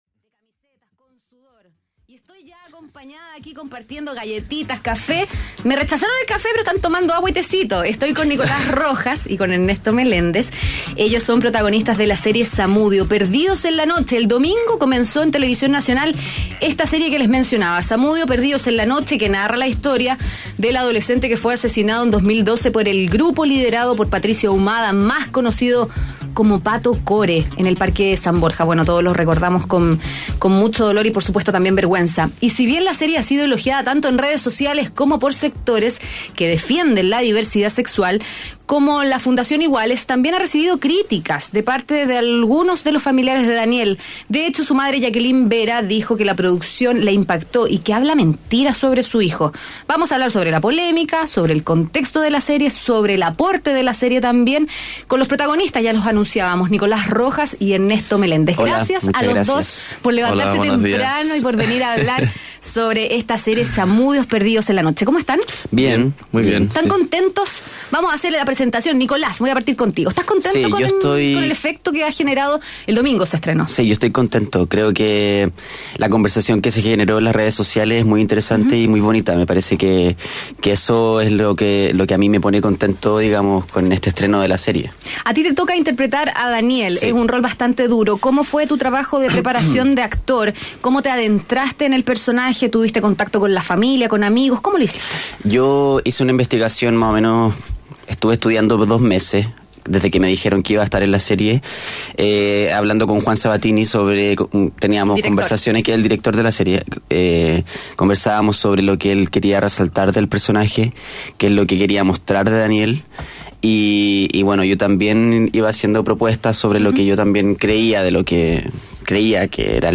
Escucha la entrevista completa realizada en Mañana Será Otro Día: